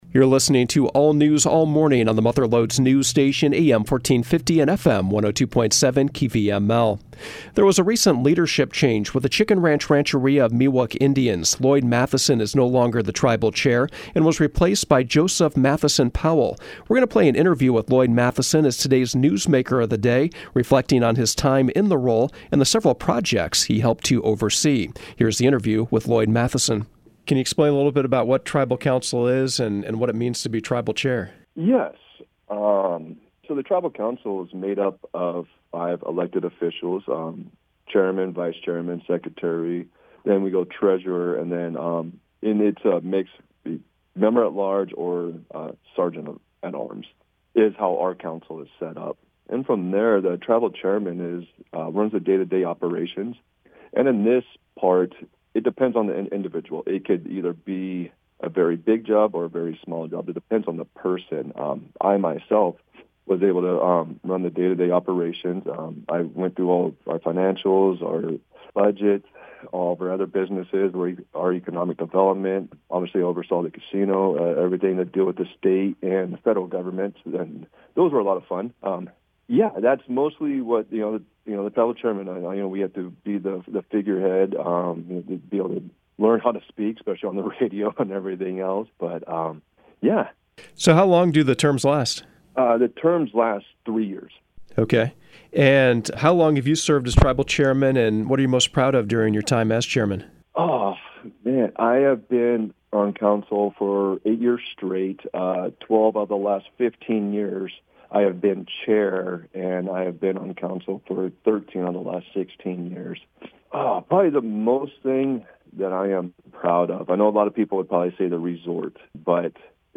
The interview is below: